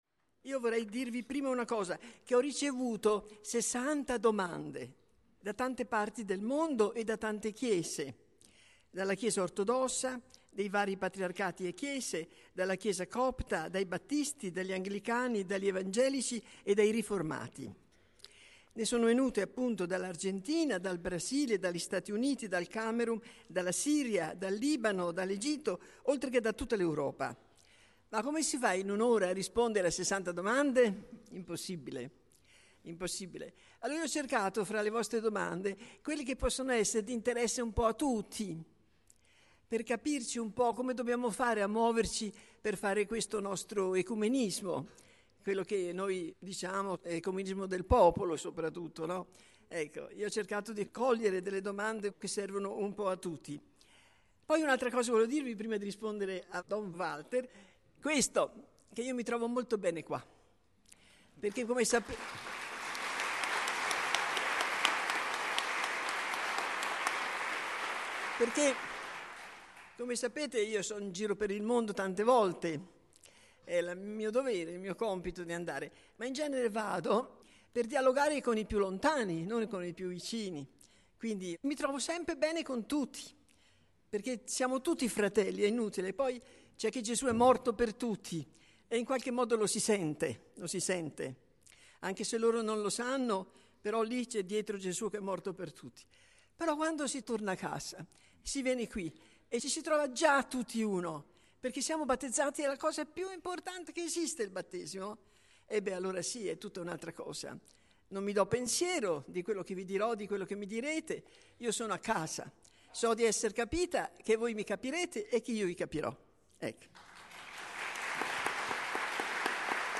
Durante il Congresso ecumenico del 2001, Chiara Lubich risponde ad alcune domande.